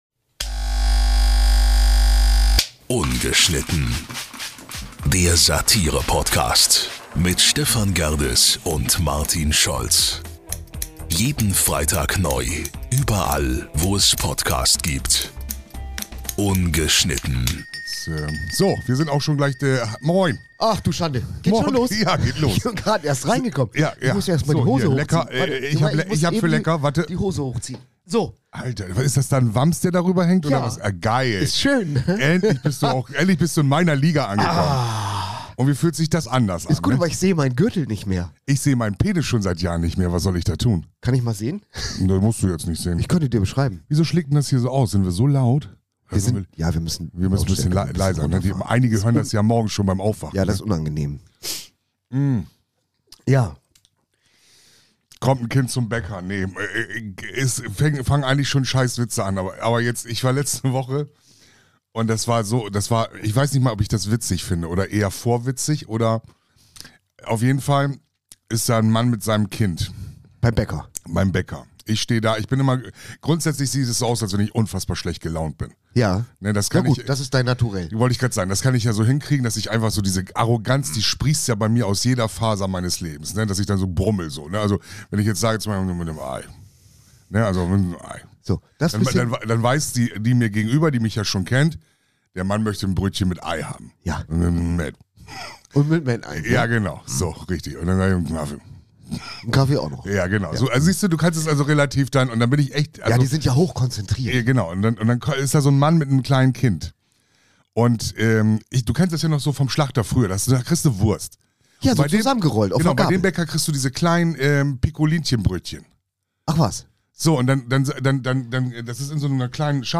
Wieder mal, eine völlig verrückte Folge, zu einer morgendlichen Unzeit aufgenommen, aber es läuft und jede Woche , wenn es wieder heißt : Ungeschnitten...